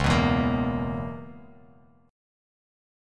Dbm13 chord